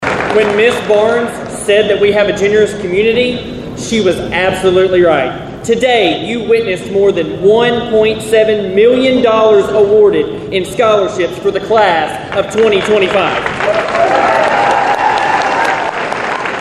The Trigg County High School class of 2025 was honored and celebrated Tuesday morning during the annual scholarship day at the high school gym.